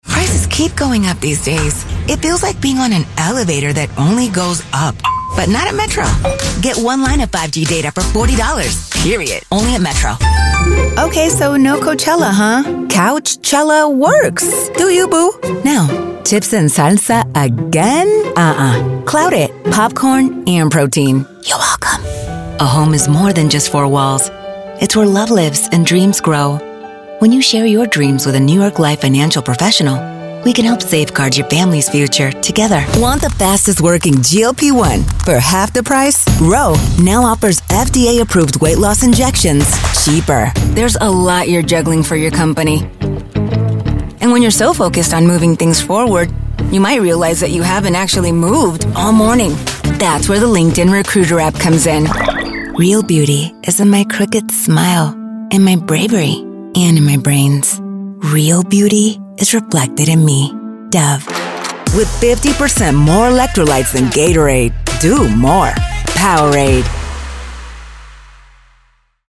accented English, announcer, caring, confessional, confident, conversational, cool, english-showcase, friendly, genuine, girl-next-door, high-energy, informative, inspirational, middle-age, mother, motivational, perky, promo, retail, smooth, thoughtful, upbeat, warm